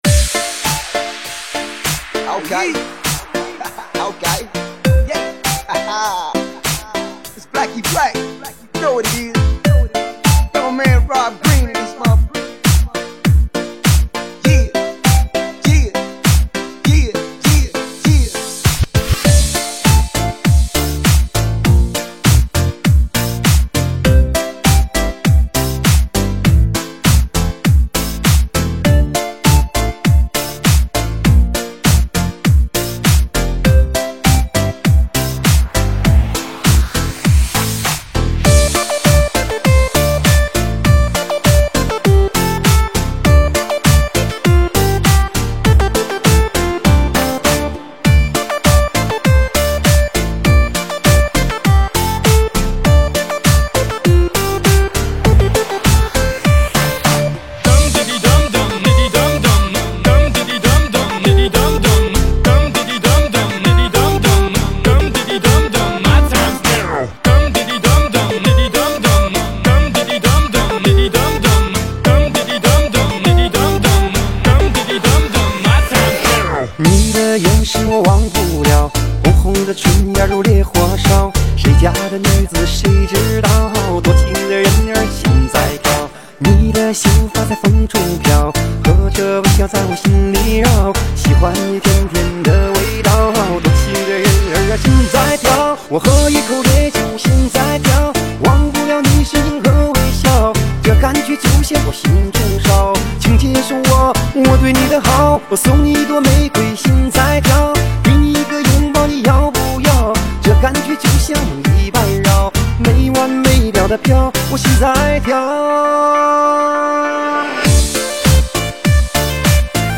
栏目：中文舞曲